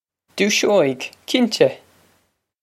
Doo-shoh-ig, kin-teh.
This is an approximate phonetic pronunciation of the phrase.